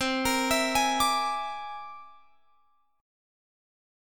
C Augmented 9th